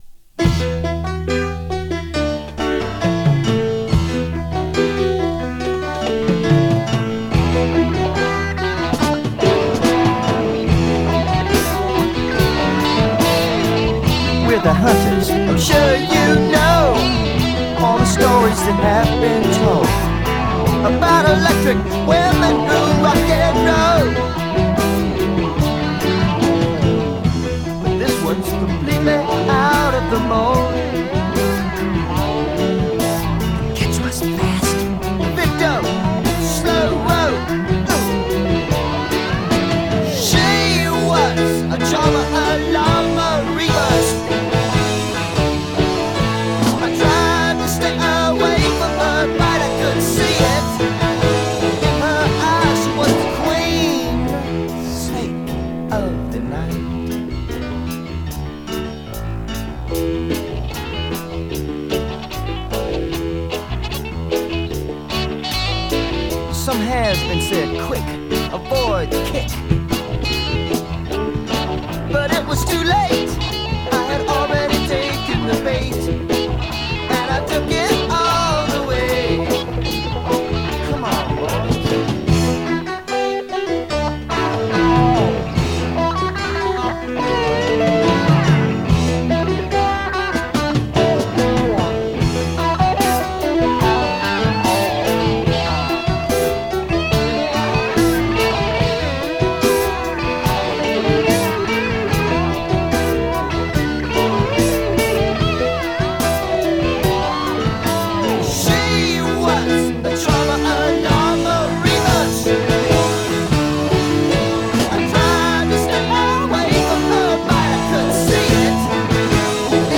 slinky, little ear worm
Category: Classic Rock, Song of the Day